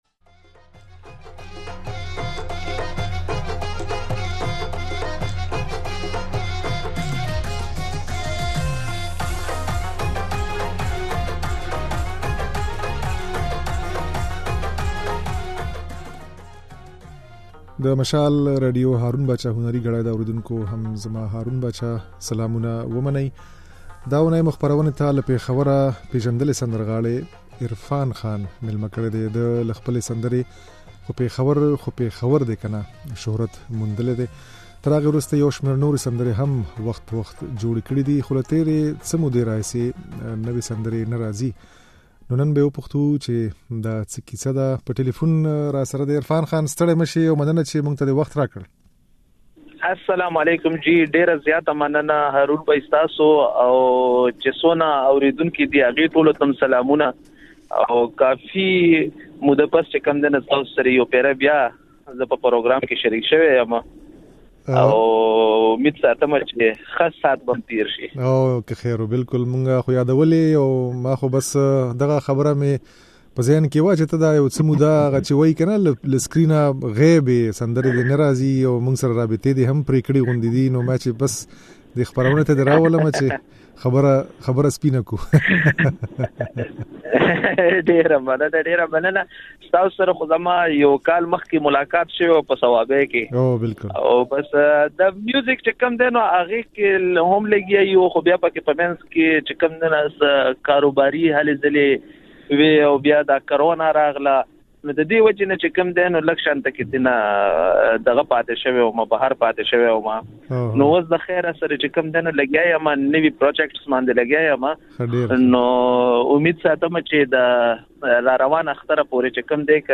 د نوموړي د ژوند او هنر په اړه د ده خبرې او ځينې سندرې يې د غږ په ځای کې اورېدای شئ.